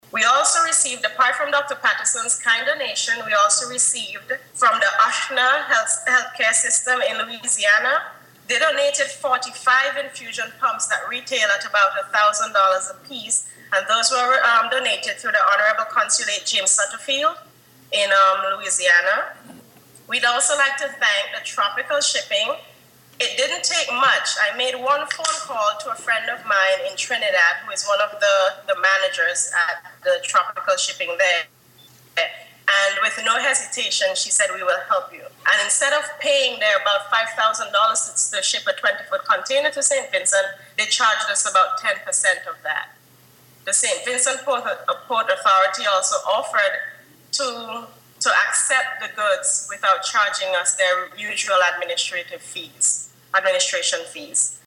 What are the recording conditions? Speaking at a handing over ceremony via Skype